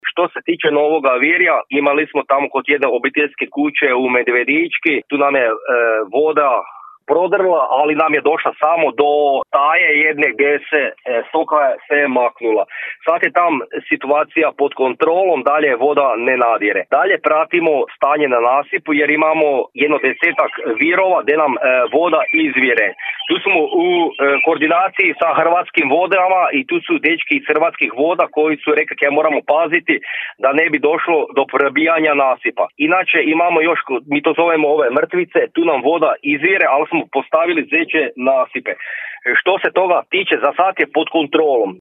U Novom Virju su postavljani zečji nasipi kako bi se kućanstva obranila od nadiranja vode, iako zasad opasnosti nema, rekao je to za Podravski radio načelnik Općine Novo Virje, Mirko Remetović;